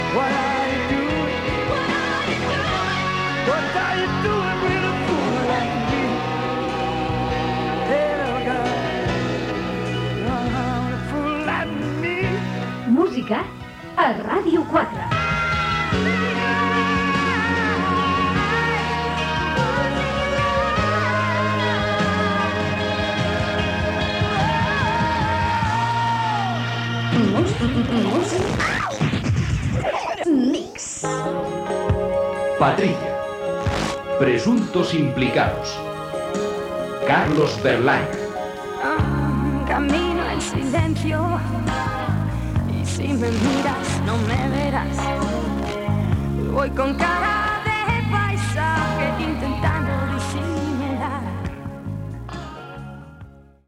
Indicatiu de l'emissora, tema musical, indicatiu del programa i presentació del següent tema Gènere radiofònic Musical